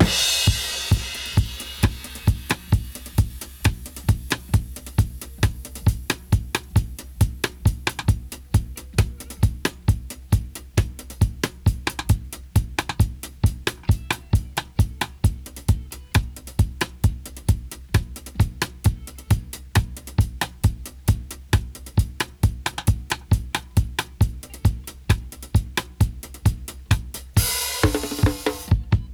131-DRY-02.wav